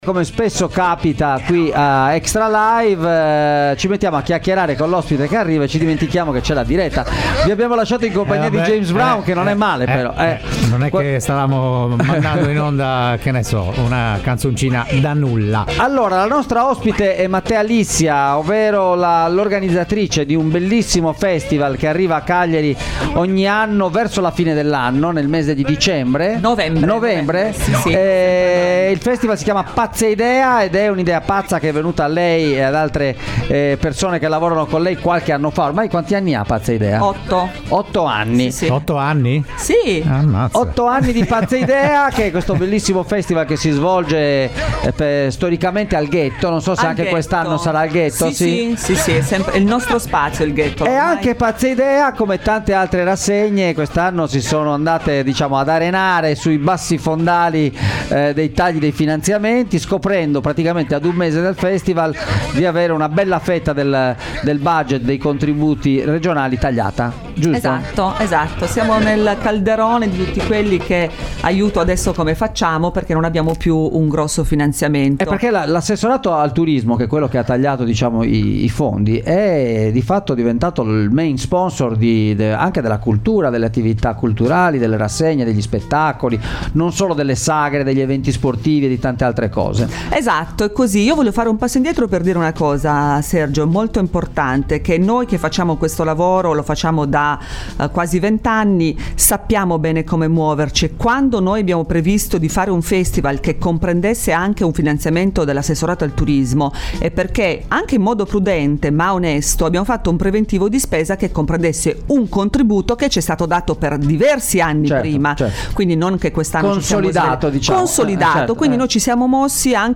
intervista
Ne abbiamo parlato in studio